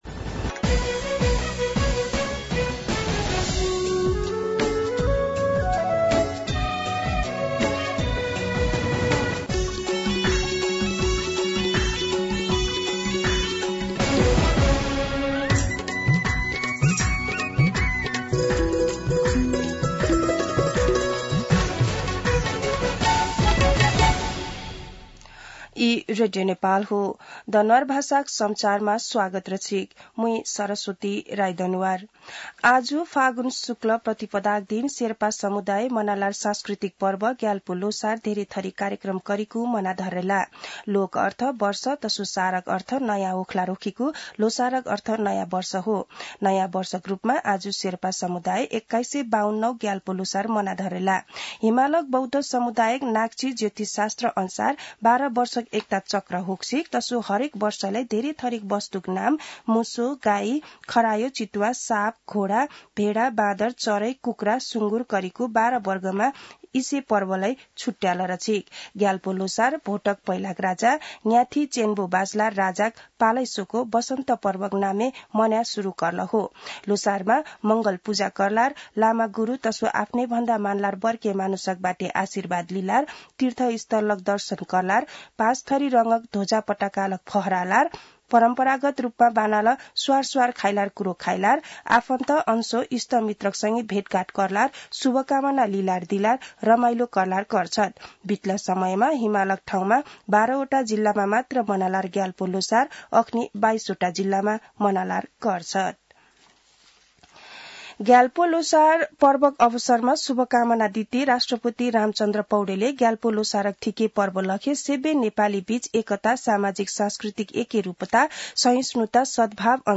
दनुवार भाषामा समाचार : १७ फागुन , २०८१
Danuwar-News-3.mp3